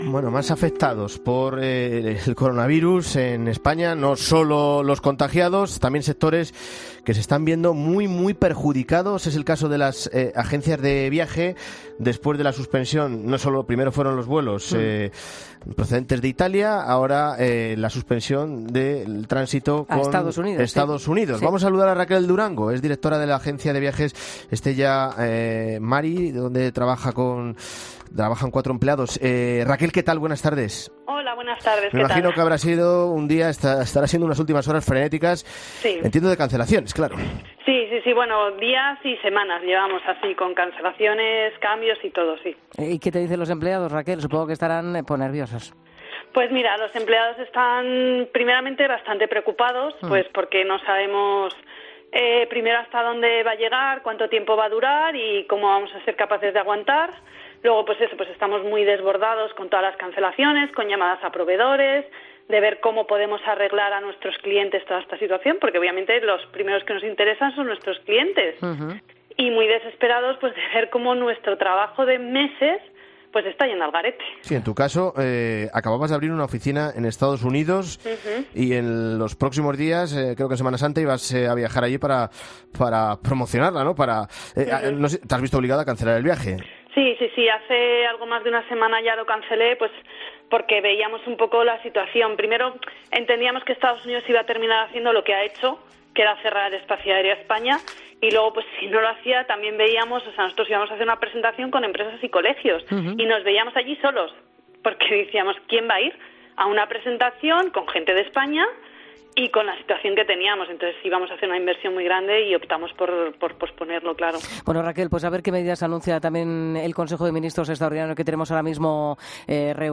En Mediodía COPE hablamos con la directora de una agencia de viajes para saber cómo les afecta la crisis del coronavirus